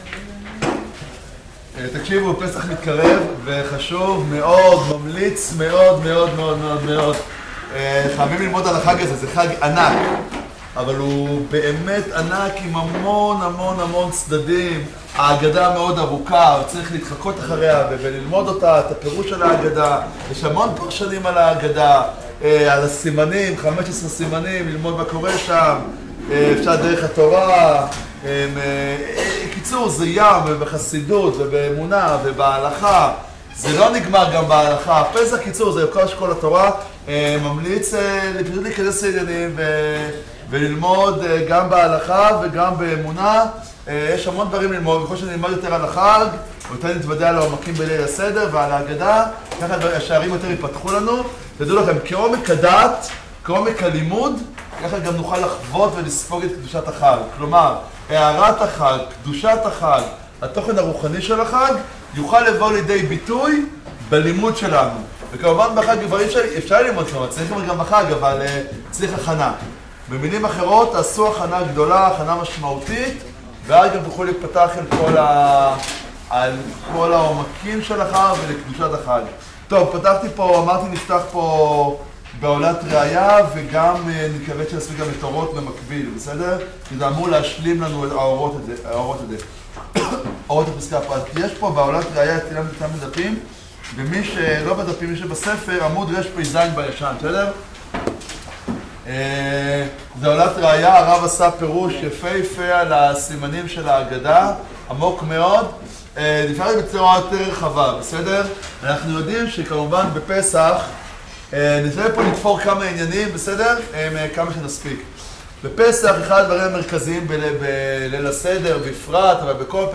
החיפזון ביציאת מצרים - שיעור לפסח